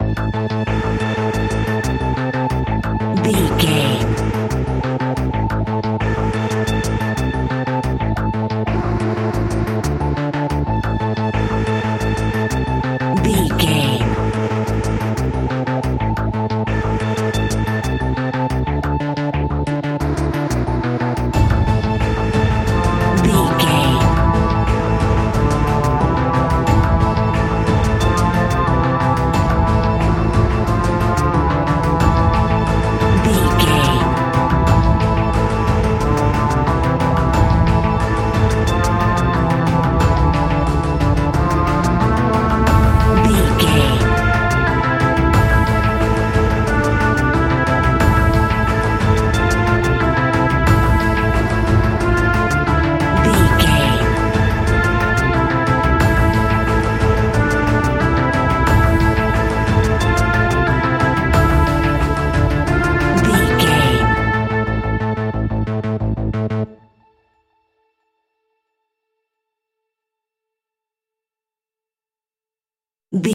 Aeolian/Minor
ominous
dark
haunting
eerie
synthesiser
drums
percussion
horror music
horror instrumentals